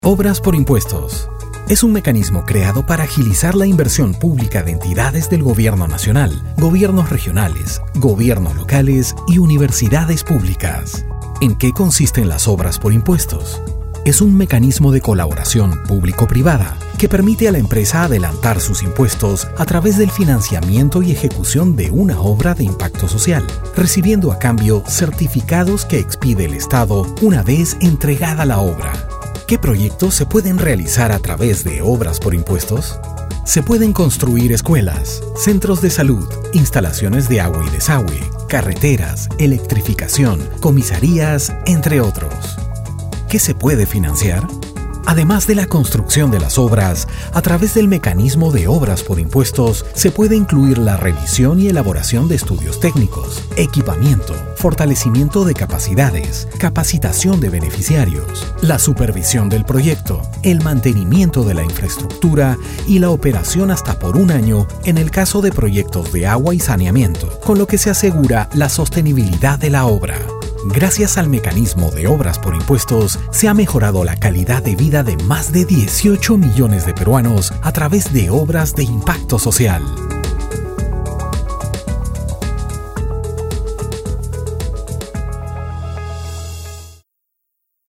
Voz versátil, cálida y de amplio rango vocal.
Sprechprobe: Industrie (Muttersprache):
Versatile, warm voice with a wide vocal range.
Professional recording studio.
Narration :Narración.mp3